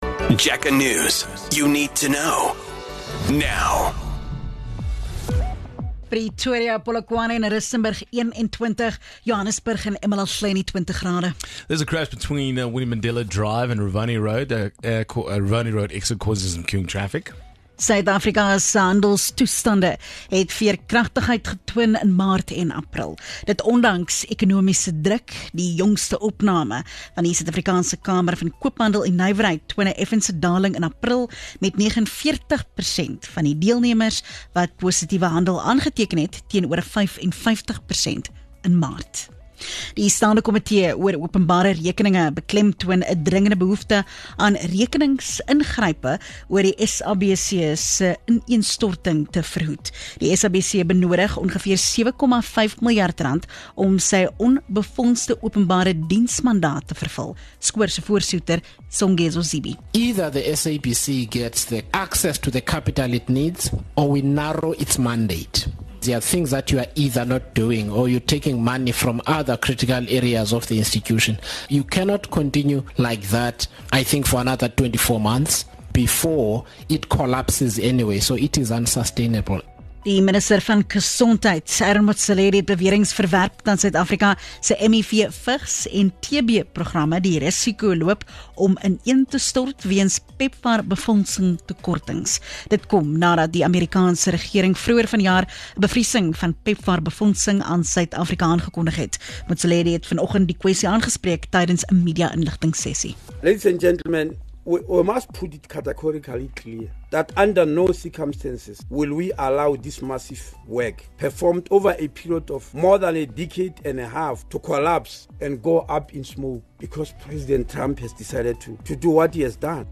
Jacaranda FM News Bulletins